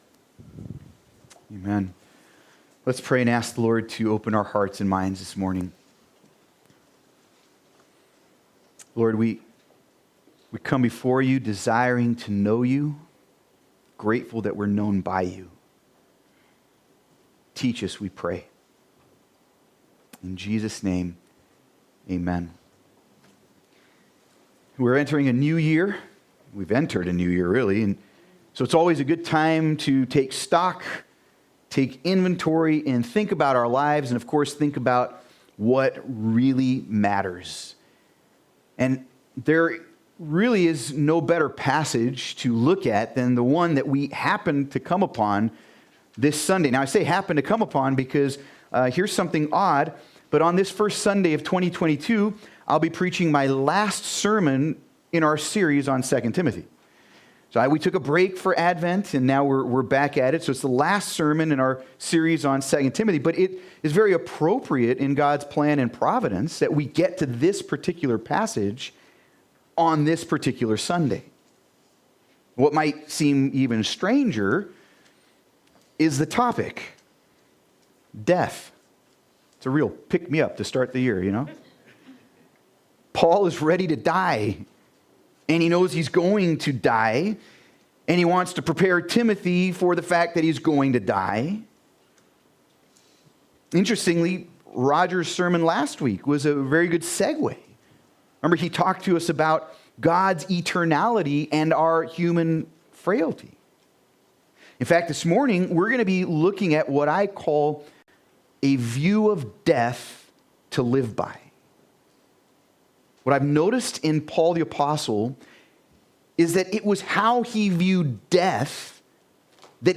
Sermon on 2 Timothy 4:6-22